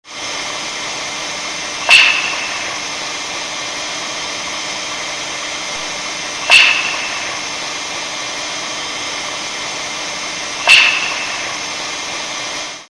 Звуки северного сияния как небесная музыка
В рамках этого мероприятия группа ученых из университета Аальто в Финляндии представили аудиозаписи звуков, сопровождающих северное сияние.
Звуки, сопровождающие северное сияние